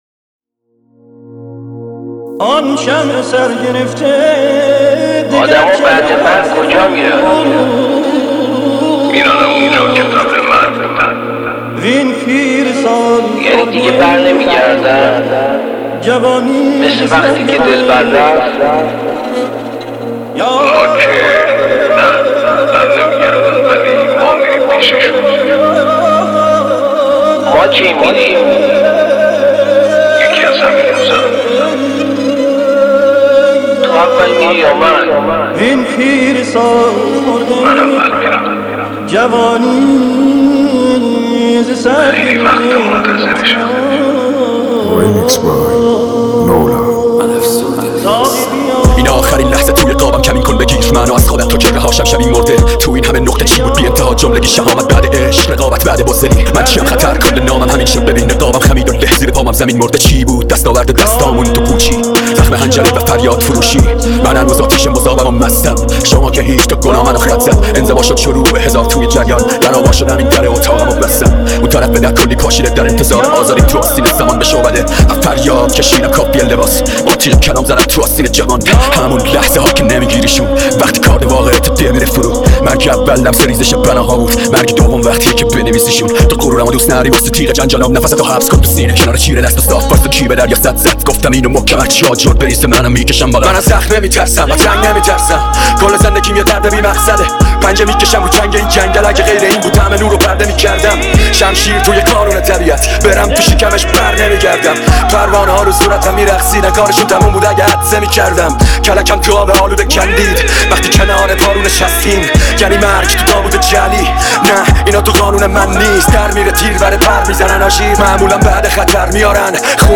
دانلود ریمیکس رپی جدید